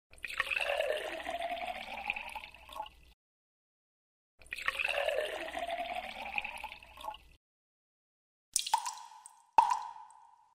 Шум наполнения чайника водой